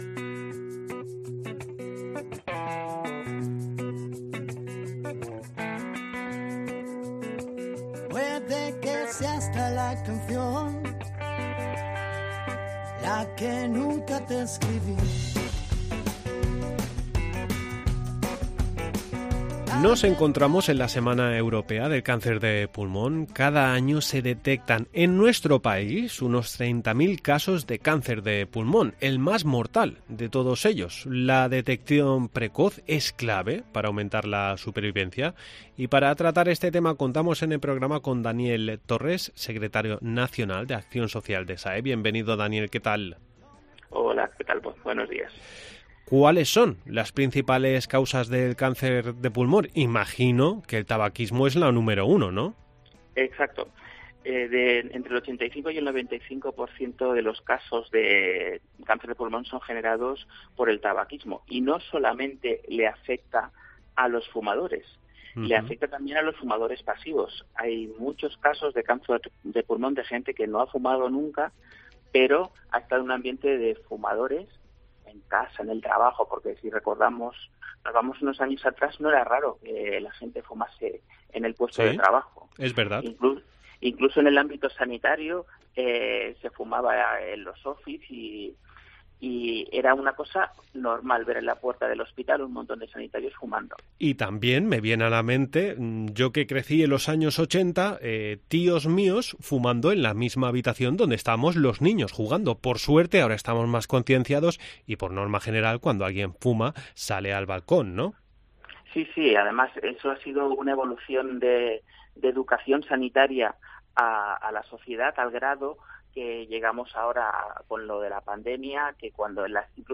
AUDIO: Nos hallamos en la semana del cáncer de pulmón.